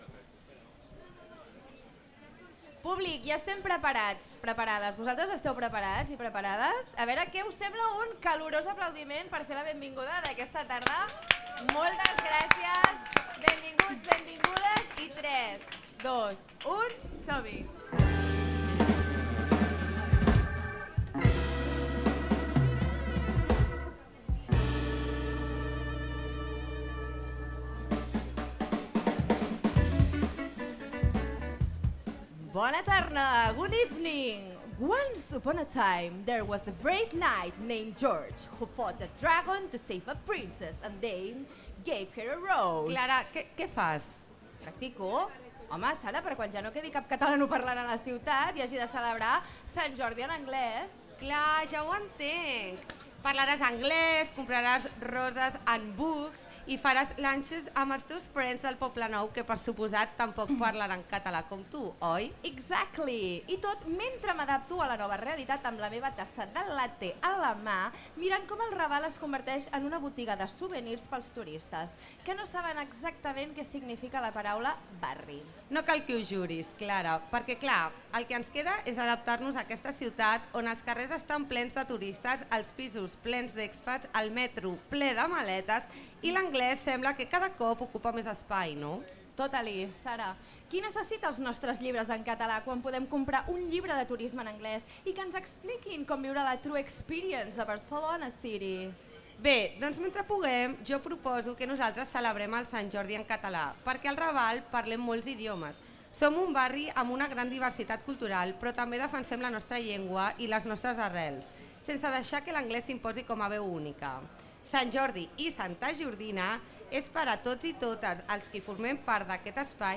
Entre campanas y dialectos nos volvemos a adentrar en el maravilloso mundo del libre albedrío, esta vez un tanto más organizado, aunque no te asustes; en el discurrir de los continuos tirones de orejas y diferentes métodos de tortura ejercidos por el profesorado más añejo, hemos dejado paso, como siempre, a lo impredecible y, también como siempre, los dones y las doñas han sido acompañadas de los ritmos musicales más suculentos, por cierto, no dejes de estar atentx a nuestra agenda musical!!!!